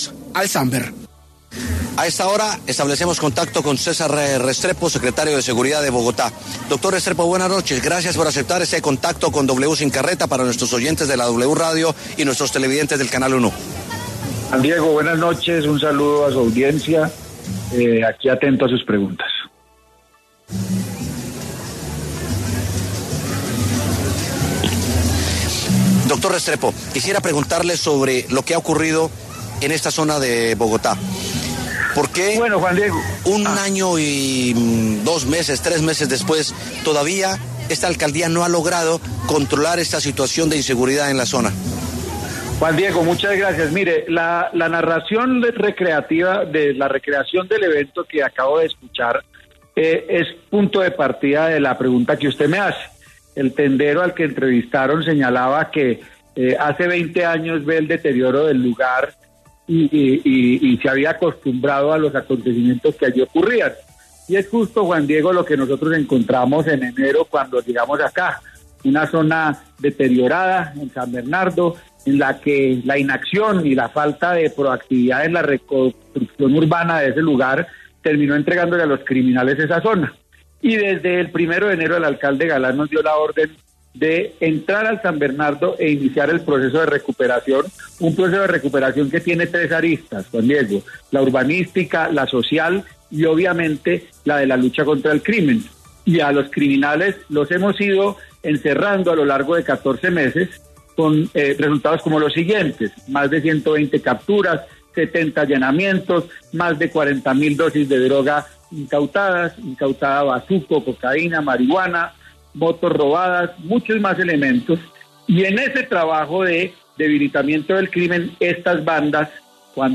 César Restrepo, secretario de Seguridad de Bogotá, habló en W Sin Carreta sobre los hechos de violencia que se han registrado los últimos días en el barrio San Bernardo.